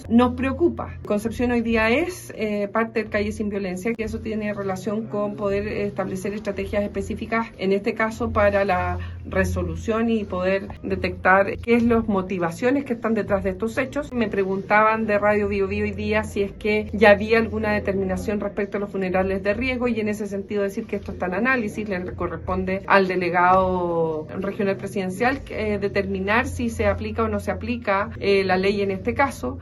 En tanto, la seremi de Seguridad Pública del Bío Bío, Paulina Stuardo, recordó que Concepción es parte del Plan Calles sin Violencia, y que se está evaluando si el funeral correspondería a uno de ese tipo.
cuna-seremi-seguridad.mp3